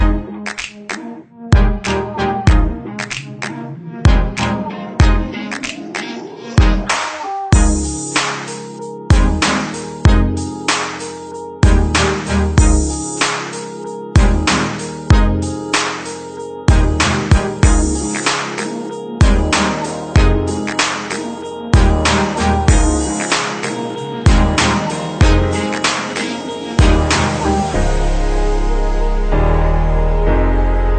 English Ringtones